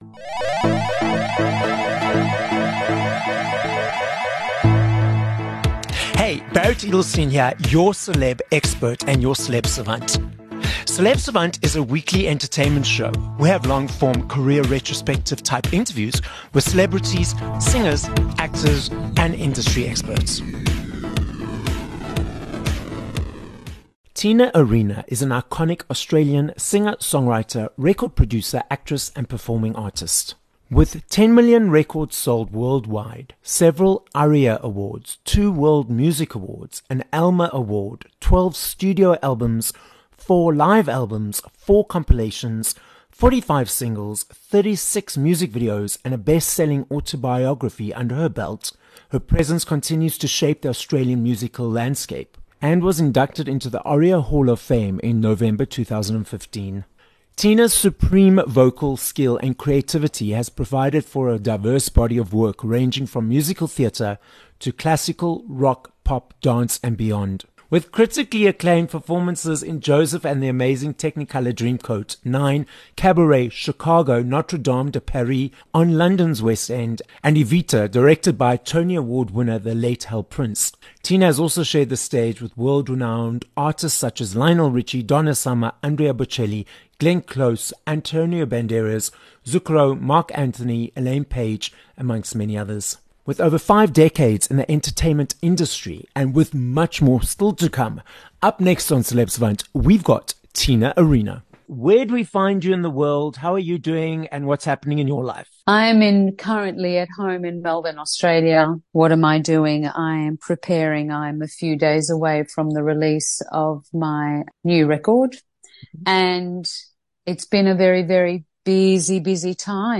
16 Jul Interview with Tina Arena